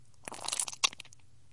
tomato gore sounds » cloth squish 1
描述：squishing tomatoes in a glove. would be good for horror gore sounds. recorded with a tascam dr05
标签： blood gore brain disgusting horror flesh intestine gross tomato
声道立体声